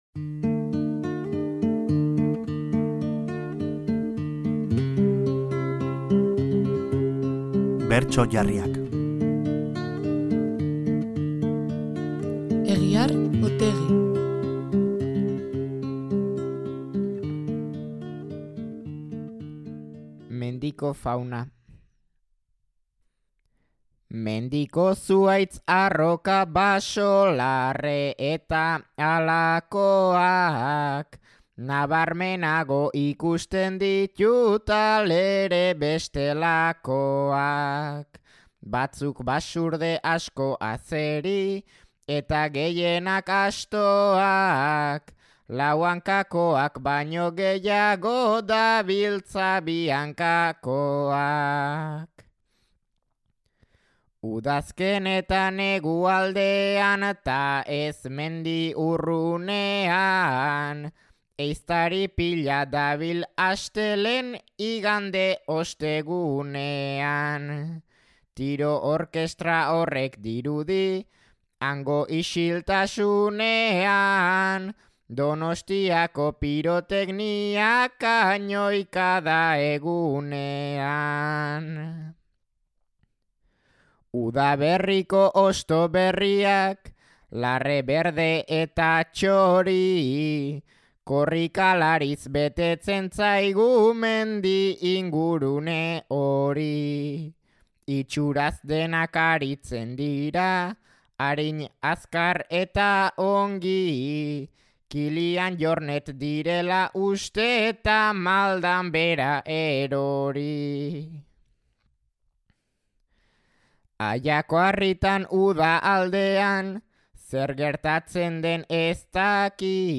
Bertso Jarriak